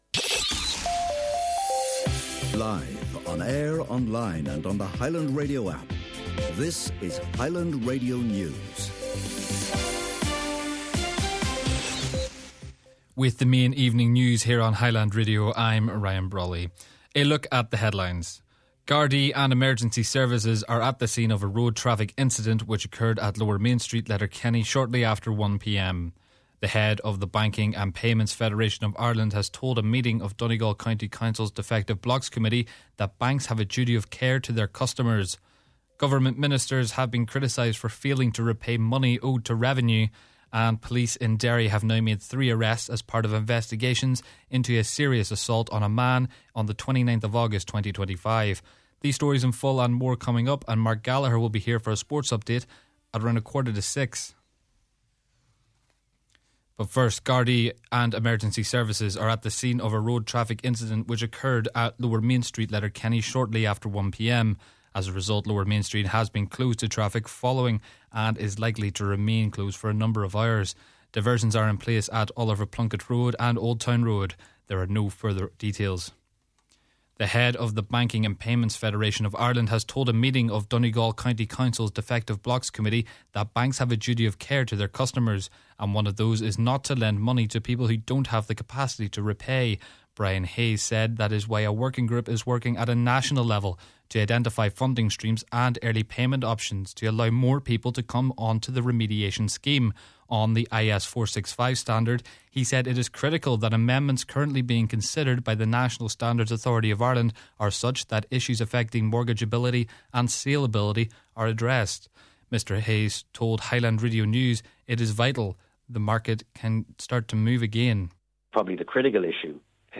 Main Evening News, Sport, Farming News and Obituary Notices – Thursday January 15th